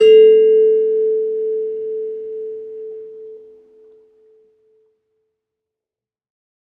kalimba1_circleskin-A3-ff.wav